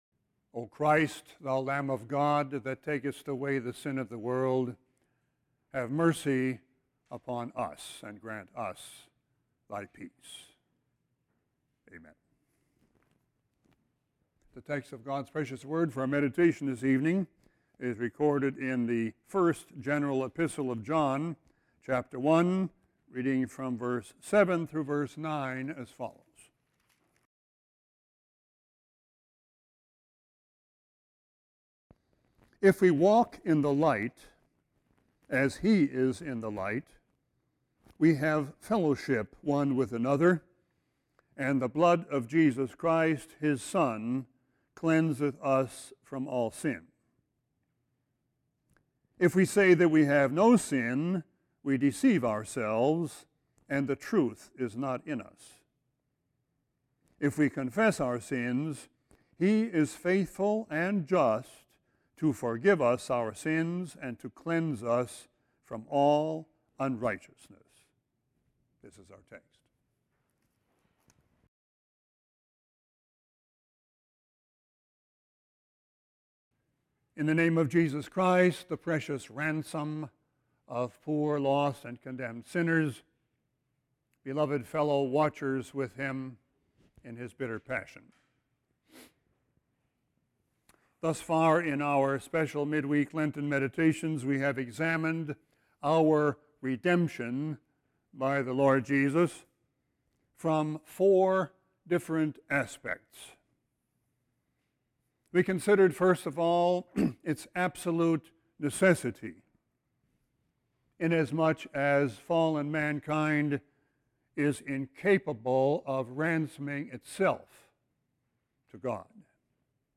Sermon 3-14-18.mp3